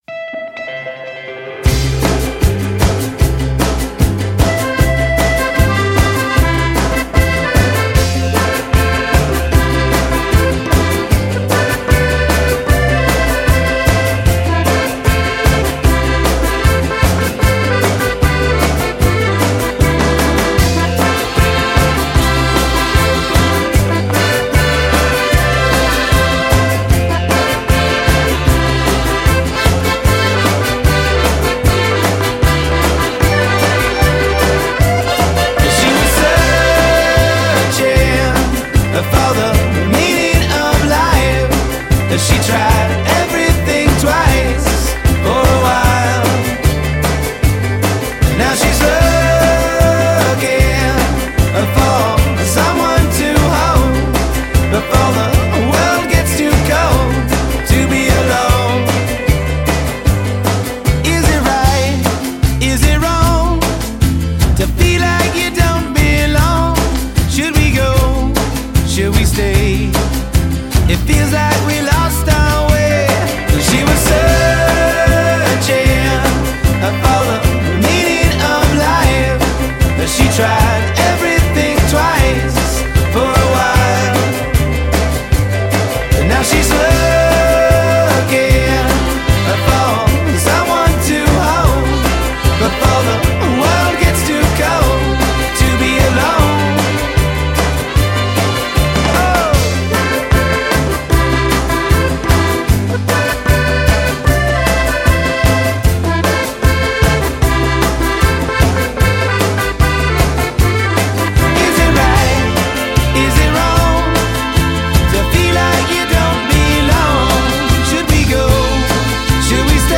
Cheerful, ear-tugging melodies?
owing more to the Tex-Mex style of Texas Tornados.